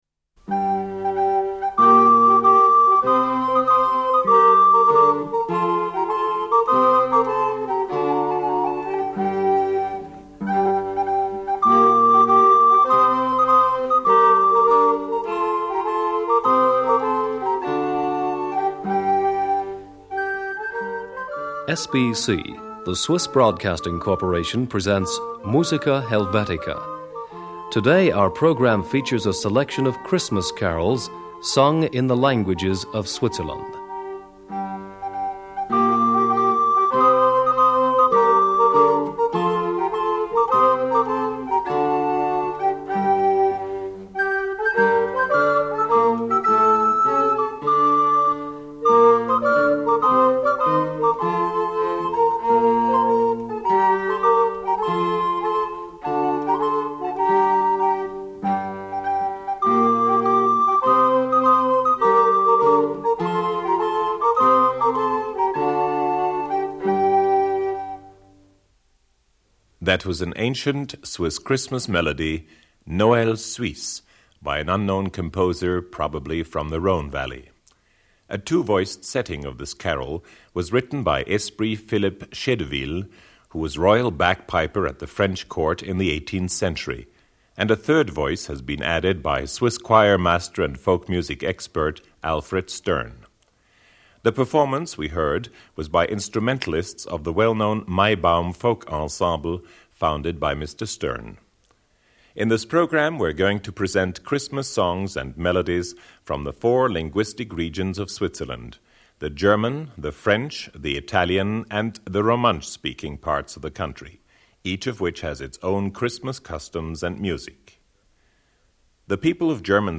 Noël suisse – Swiss Christmas.Anonymus. Maibaum Instrumental Folk Ensemble, Zürich.
Female Voices of the Choir of the Blind, Berne.
Mixed Choir…